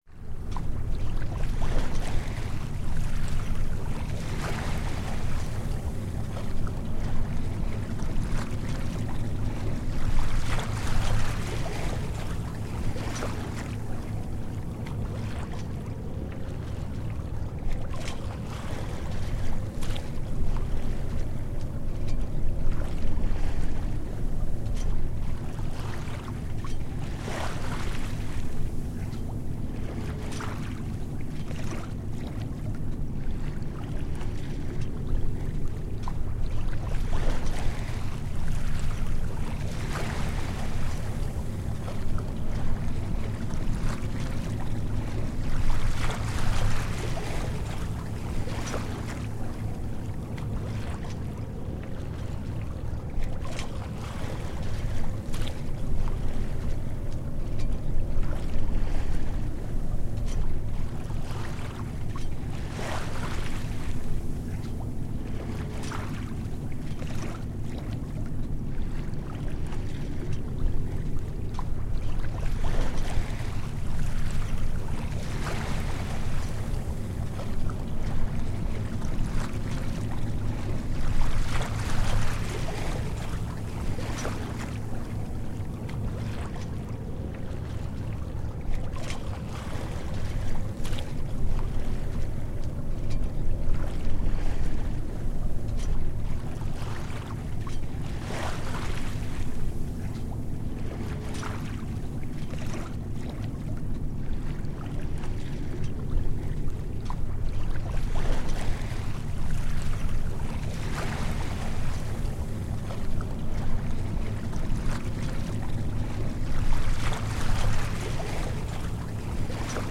На этой странице собраны натуральные звуки пляжа: шум волн, легкий бриз, детский смех на песке и другие уютные аудиофрагменты.
Тихая вода есть, но это городской пляж